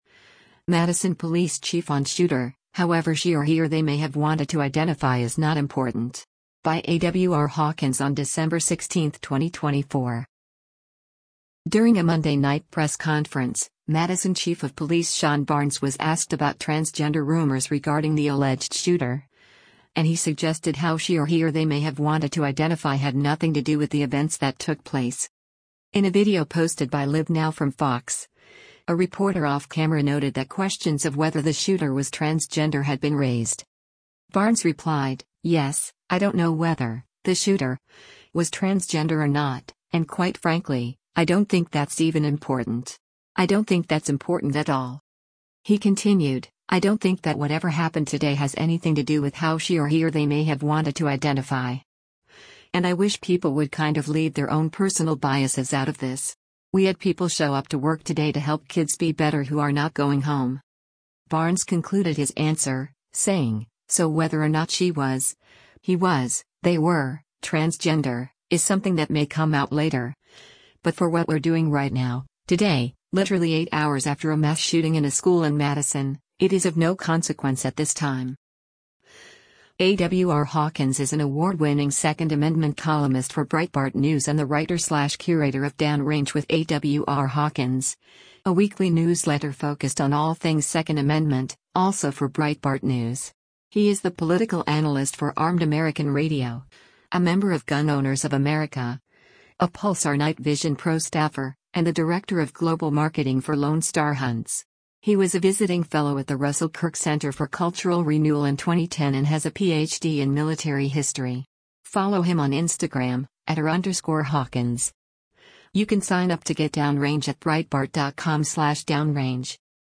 During a Monday night press conference, Madison Chief of Police Shon Barnes was asked about transgender rumors regarding the alleged shooter, and he suggested how “she or he or they may have wanted to identify” had nothing to do with the events that took place.
In a video posted by LiveNOW from Fox, a reporter off-camera noted that questions of whether the shooter was transgender had been raised.